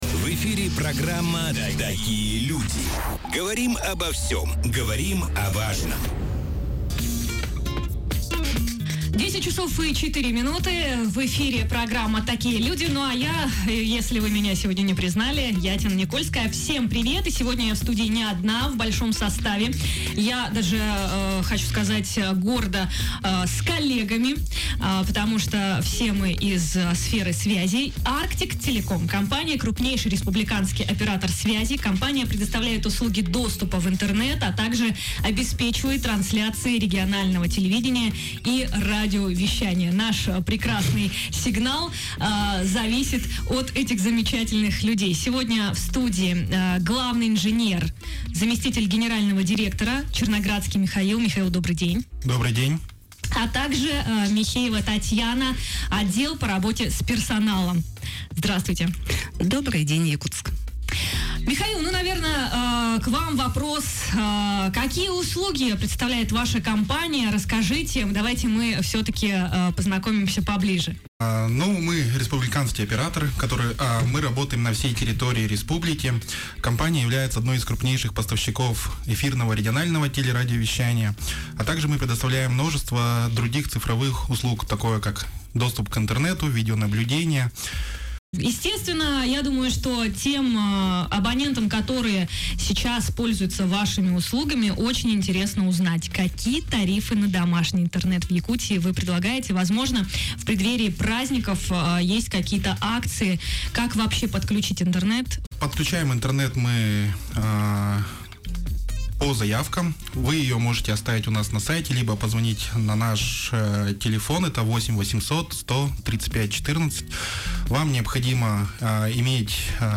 “Держим связь!” – прямой эфир на СТВ-радио